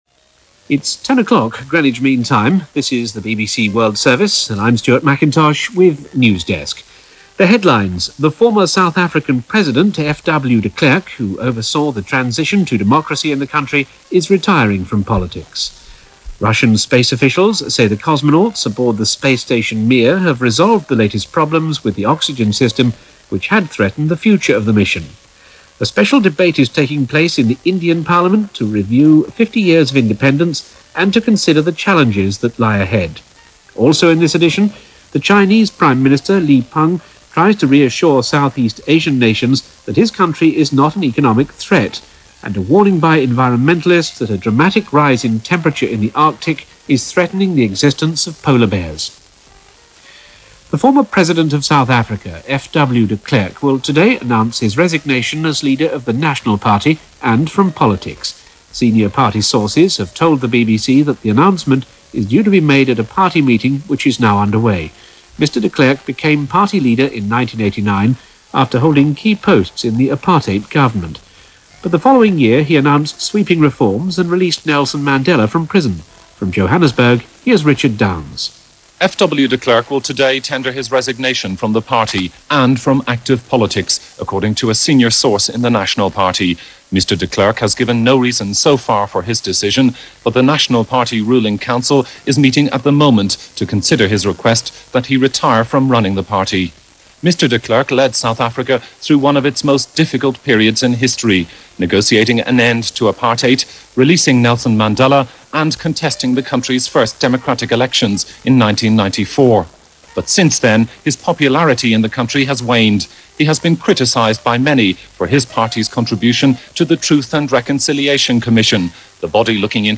De Klerk Steps Down - Fixing Mir - Rise Of Executions In China - August 26, 1997 - BBC World Service Newsdesk.